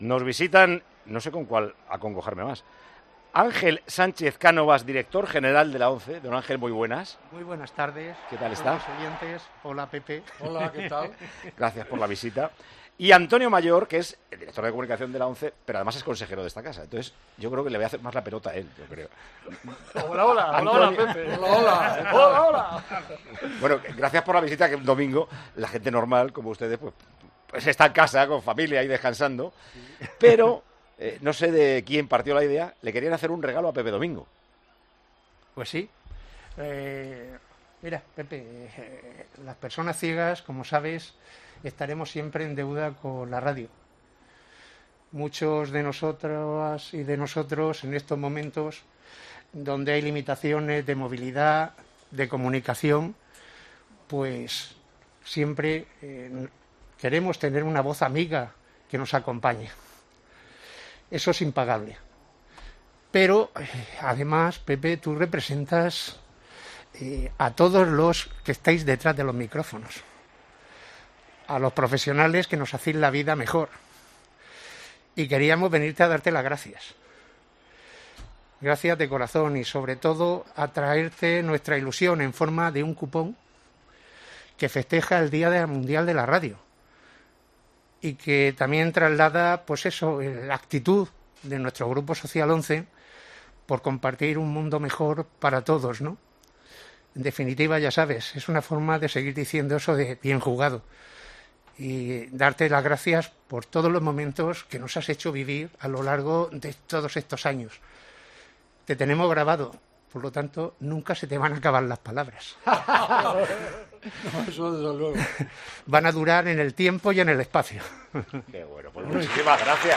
junto a Paco González y Pepe Domingo Castaño en el estudio de Tiempo de Juego